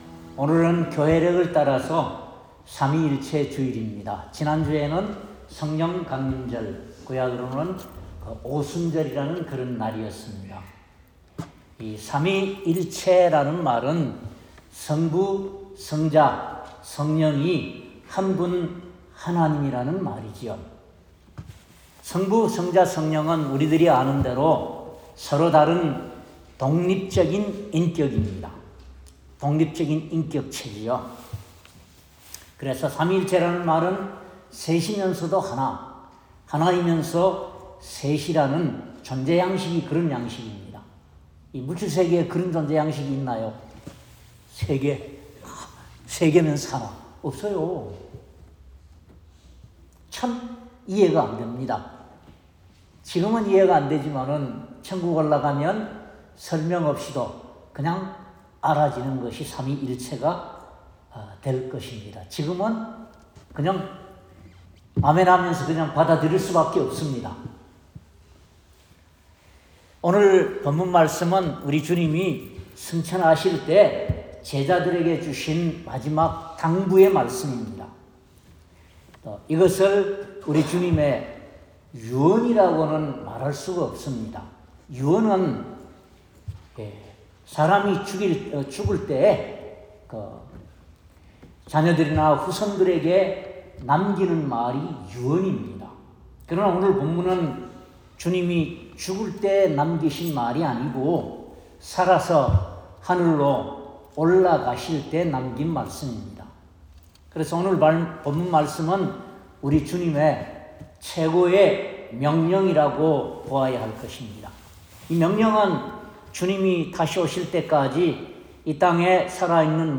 마 28:16-20절 Service Type: 주일예배 오늘은 삼위일체(The Trinity